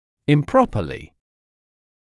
[ɪm’prɔpəlɪ][им’пропэли]неправильно